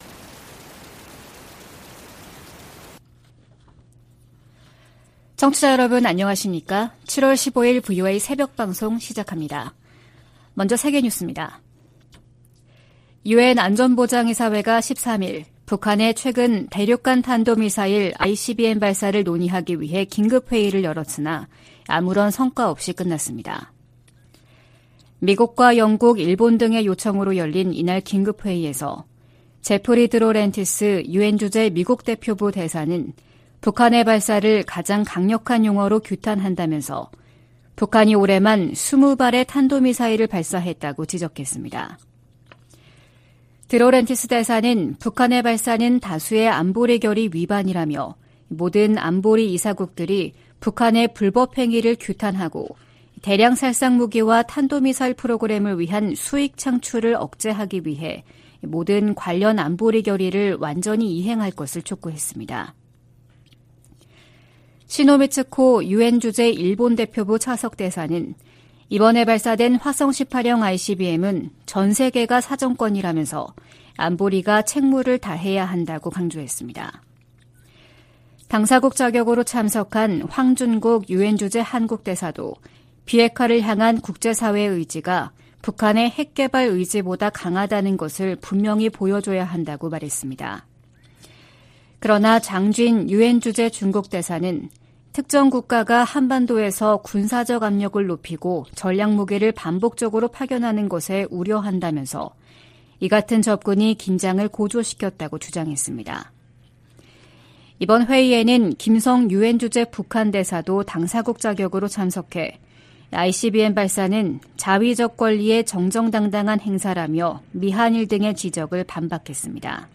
VOA 한국어 '출발 뉴스 쇼', 2023년 7월 15일 방송입니다. 미한일과 유럽 나라들이 북한의 대륙간탄도미사일(ICBM) 발사 문제를 논의한 유엔 안보리 회의에서 북한을 강력 규탄하며 안보리의 단합된 대응을 거듭 촉구했습니다. 김정은 북한 국무위원장이 할 수 있는 최선의 방안은 대화 복귀라고 미 백악관이 강조했습니다. 한국 정부가 북한의 ICBM 발사에 대응해 정경택 인민군 총정치국장 등 개인 4명과 기관 3곳을 제재했습니다.